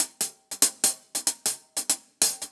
Index of /musicradar/ultimate-hihat-samples/95bpm
UHH_AcoustiHatC_95-03.wav